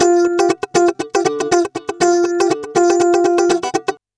120FUNKY09.wav